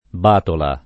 [ b # tola ]